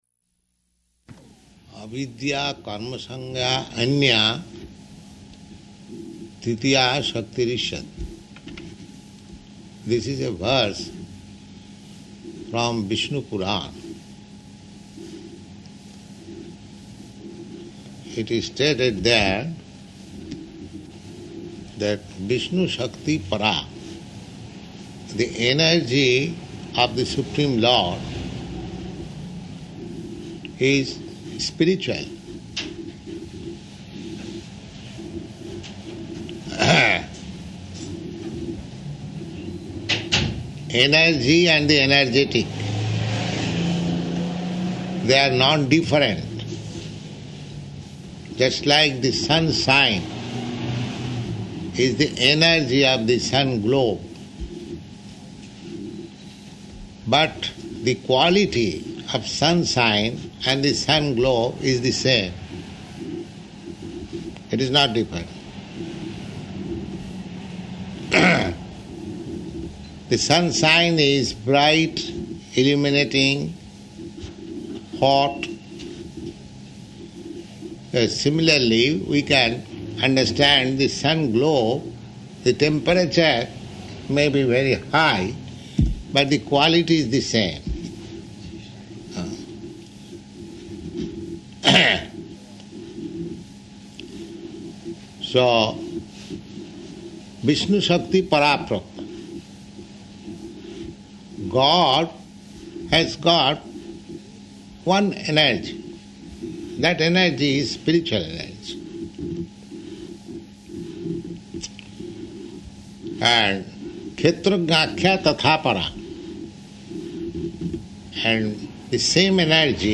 Initiation Lecture
Initiation Lecture --:-- --:-- Type: Initiation Dated: August 27th 1969 Location: Hamburg Audio file: 690827IN-HAMBURG.mp3 Prabhupāda: ...avidyā-karma-saṁjñānyā tṛtīyā śaktir iṣyate [ Cc.